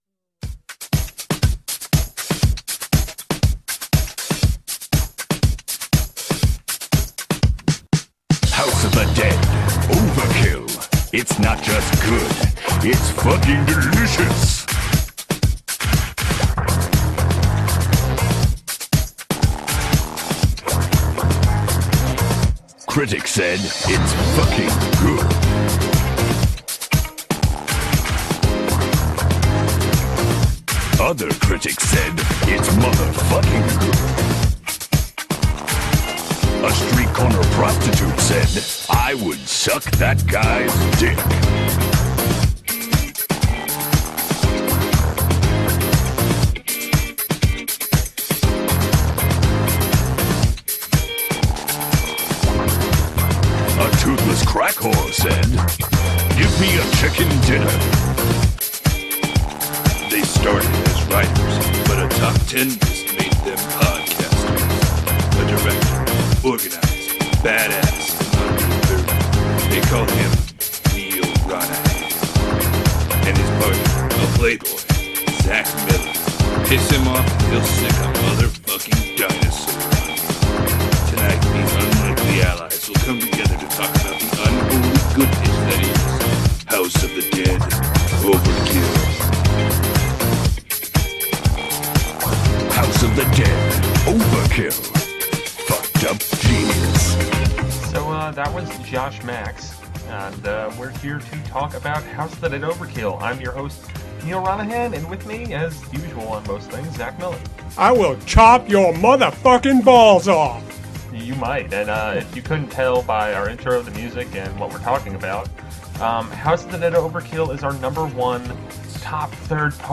We touch on the messed-up plot, the non-stop humor, and we curse a whole lot. Note: This game is totally rated M for Mature, and the songs we use in this segment and the content discussed ain't exactly for children.